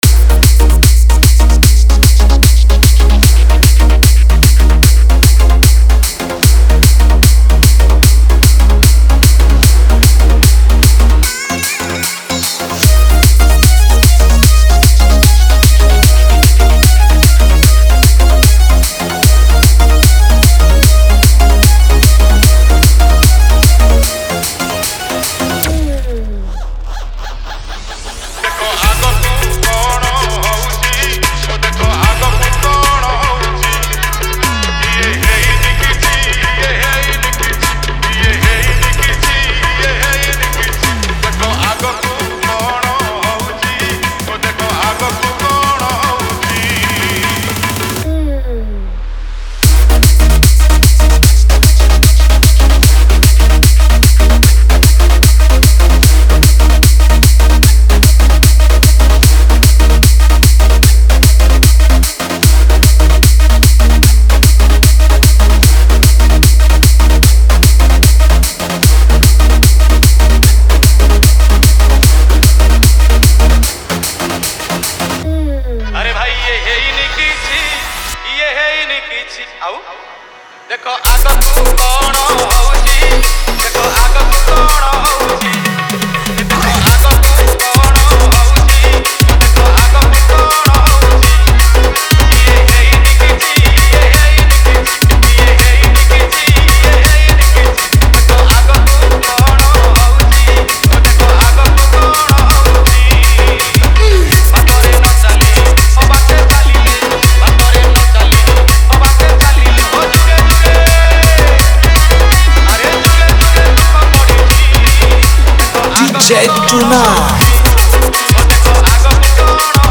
Bhajan Dj Song Collection 2021 Songs Download